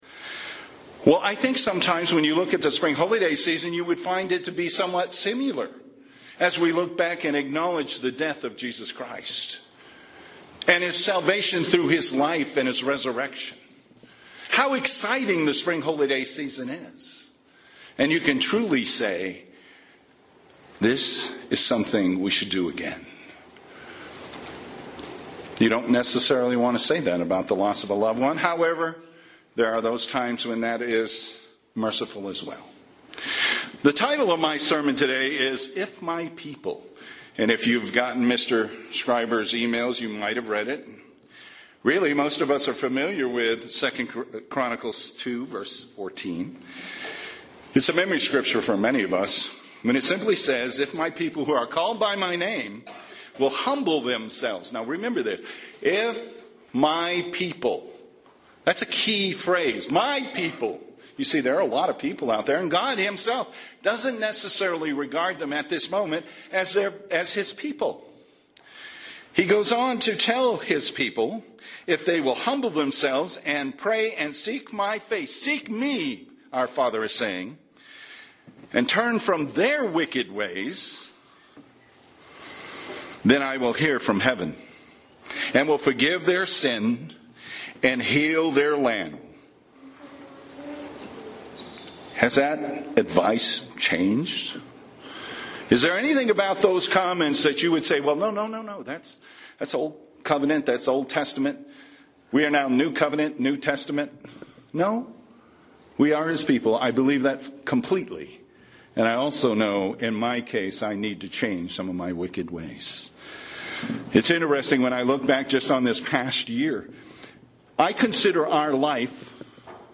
Sermons
Given in Albuquerque, NM